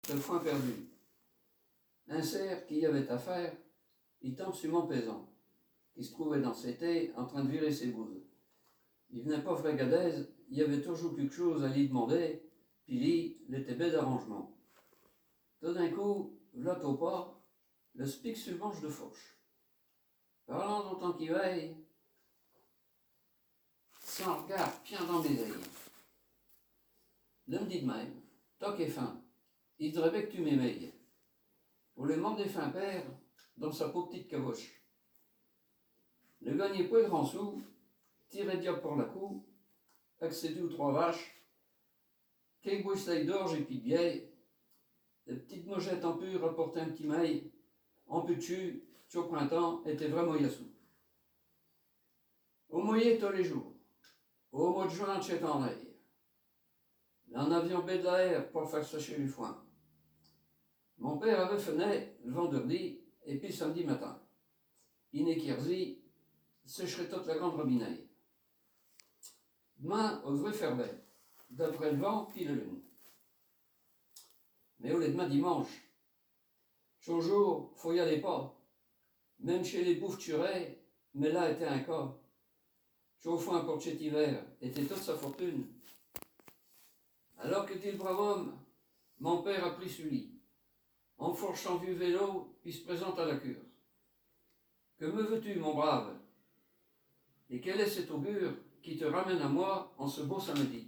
Genre poésie
Poésies en patois
Catégorie Récit